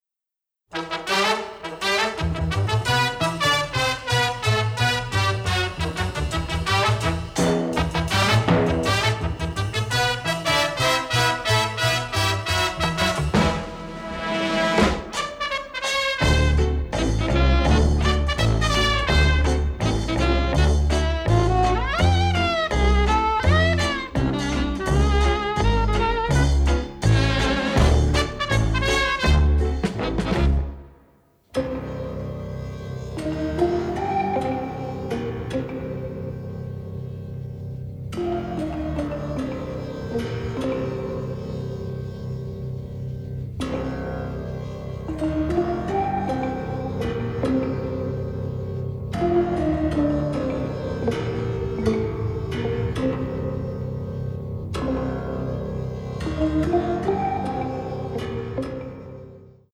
BONUS TRACKS (Mono)